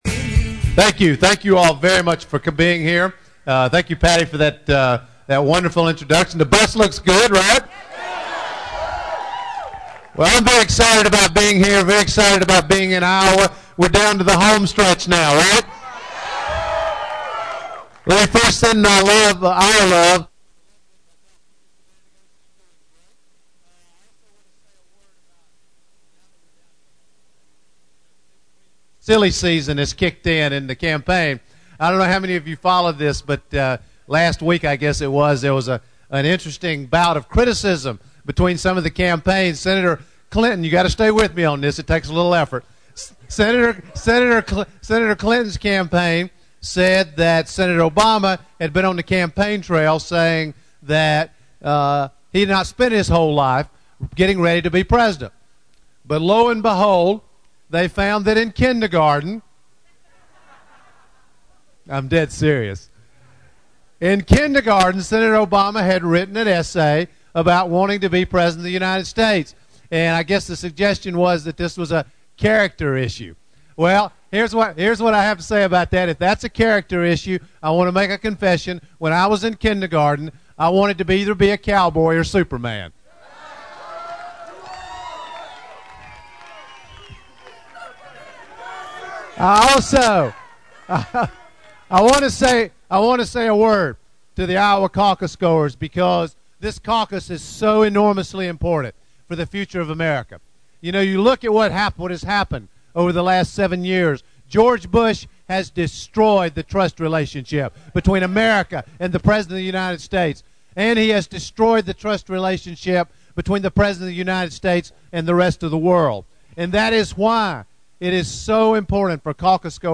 Presidential hopeful John Edwards is asking Iowans to choose a "trustworthy" candidate from among the Democrats running for the White House. The Edwards campaign rolled its red-white-and-blue campaign bus into the convention center in downtown Des Moines today for an event that saw Edwards kick-off the closing phase of his Iowa Caucus campaign.
edwardsbusrally.mp3